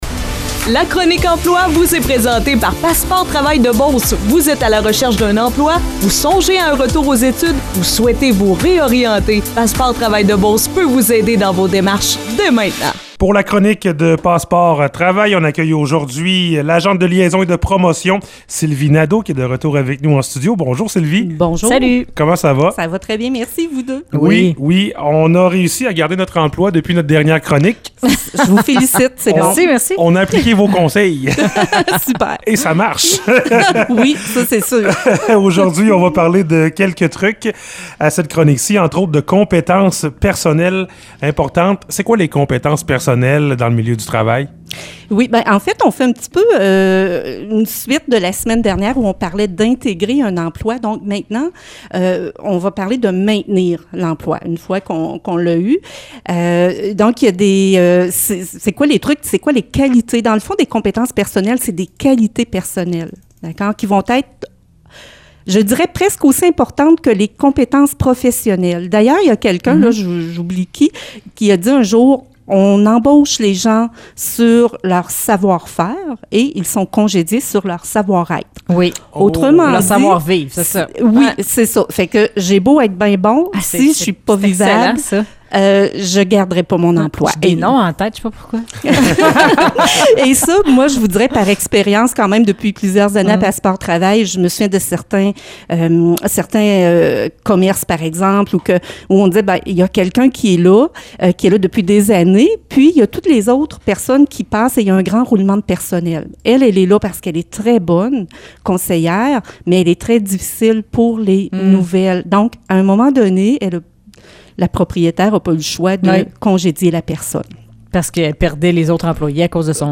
Vous avez manqué notre chronique de l'emploi sur les ondes de Mix 99,7 ou vous désirez tout simplement la réécouter, alors cliquez sur le lien.